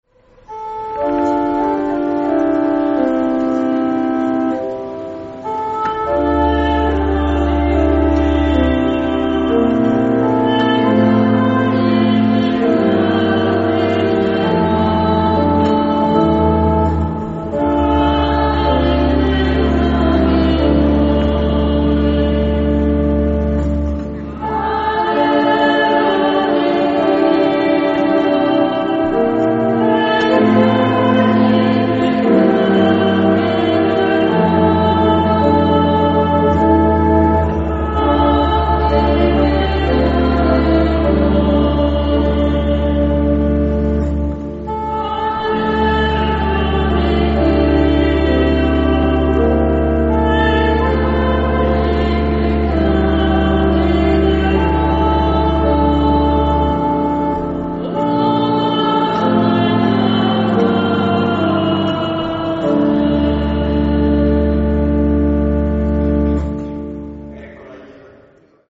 ASSOLO
...panoramica traversale alla fine della cerimonia...